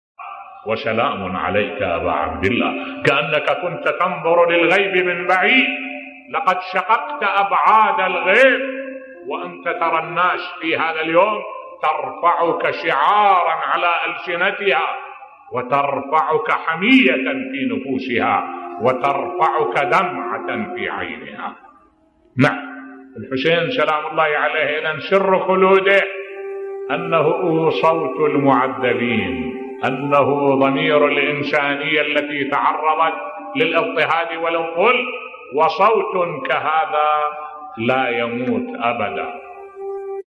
ملف صوتی صوت الإمام الحسين (ع) لن يموت ابدا بصوت الشيخ الدكتور أحمد الوائلي